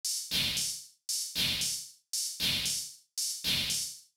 MOO Beat - Mix 9.wav